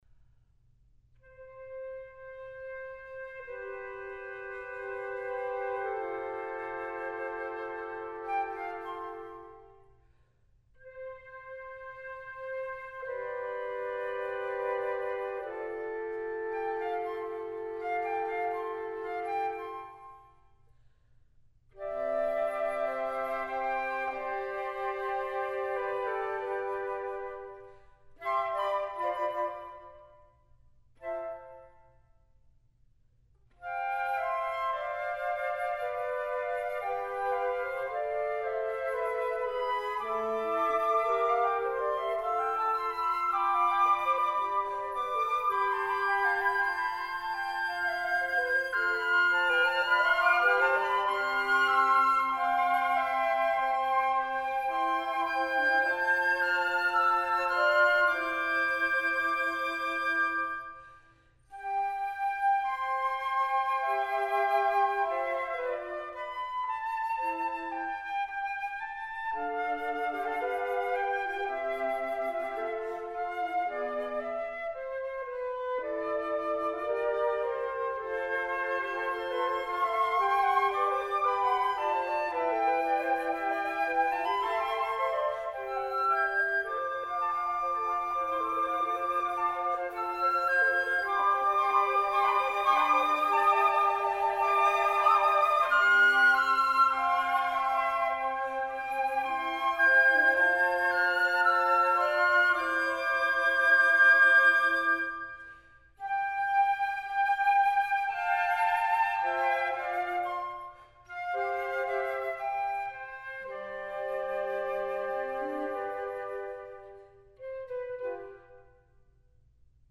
Partitions pour ensemble flexible, 8-voix.